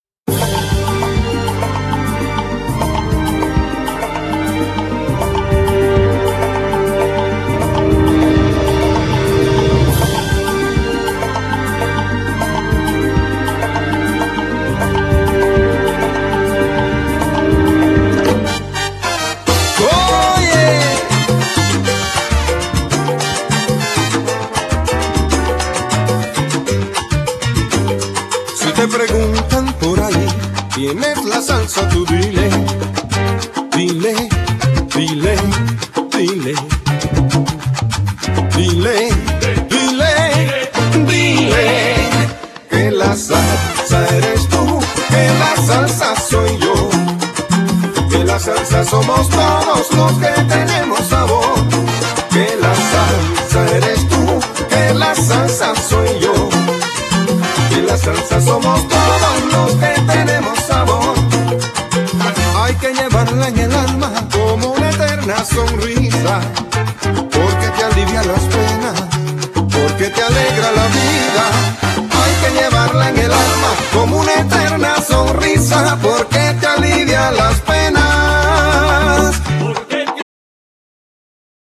Genere : Pop Latin
Ritmo coinvolgente caratteristico di questo genere musicale